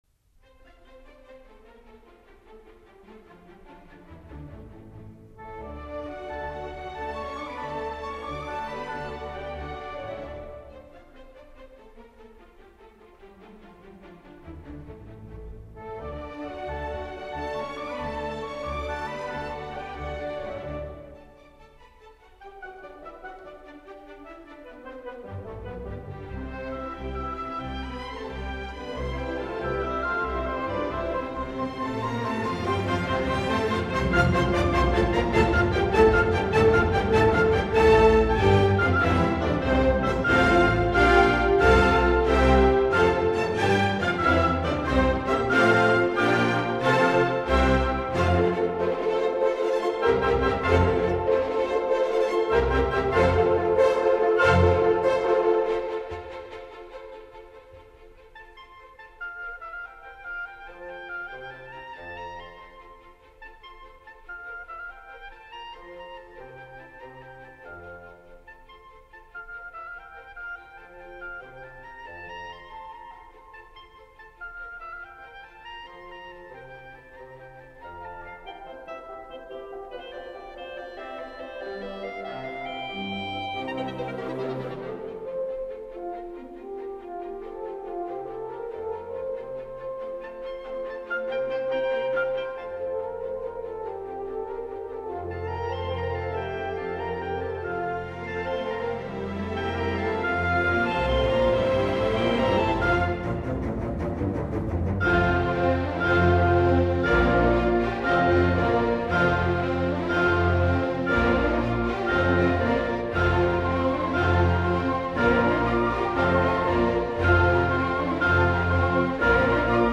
il violoncellista